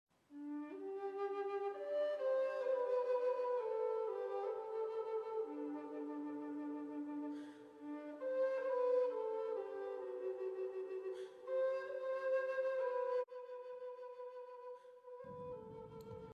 Вивальди - Флейта, вроде из какого-то советского фильма?
fleyta.mp3